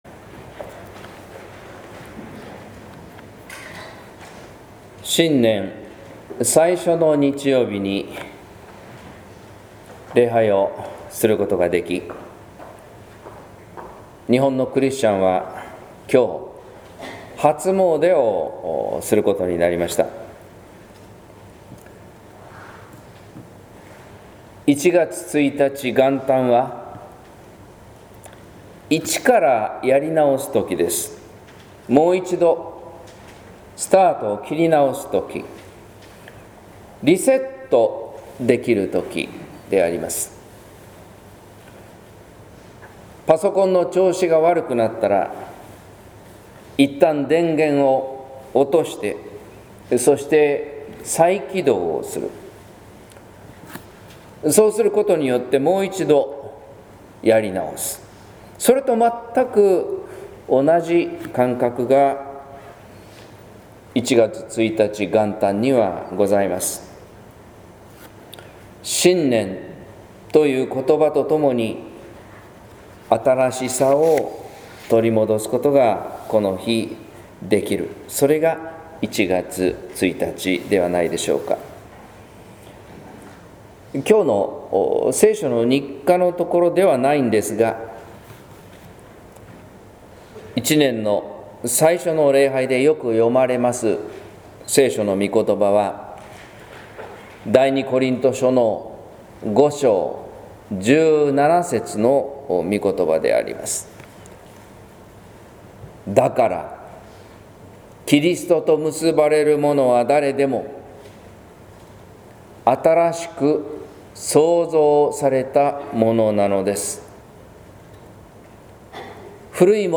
説教「向き直って新たに」（音声版） | 日本福音ルーテル市ヶ谷教会